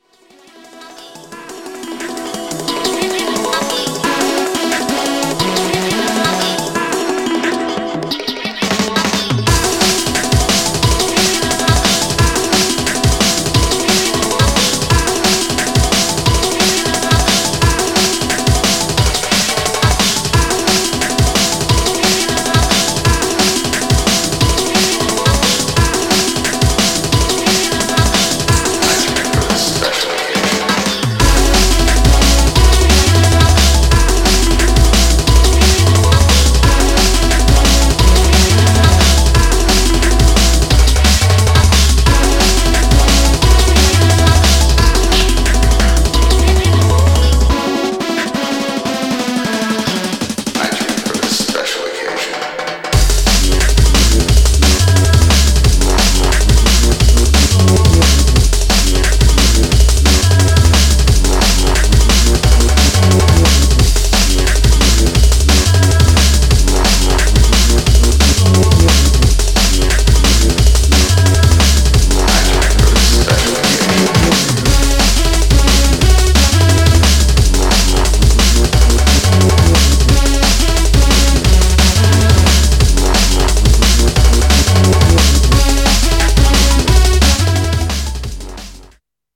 Styl: Drum'n'bass Vyd�no